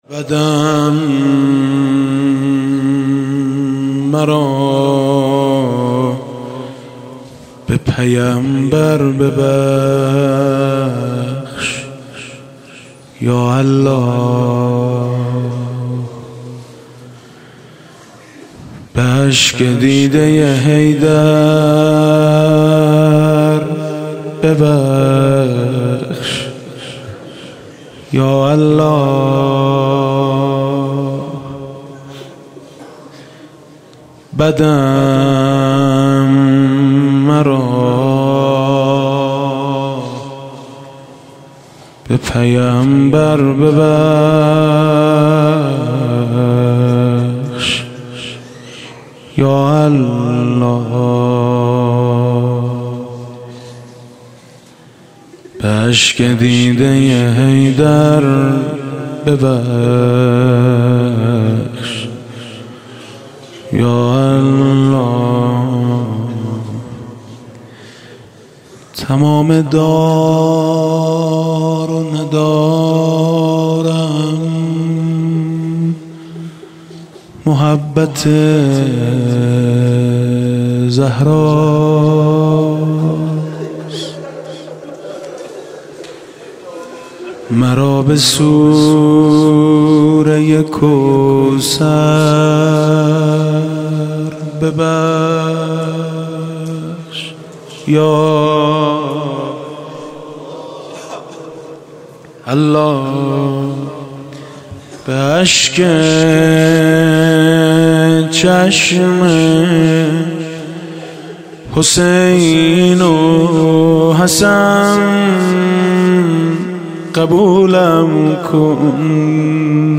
دانلود مناجات شب پنجم ماه رمضان الکریم با نوای حاج میثم مطیعی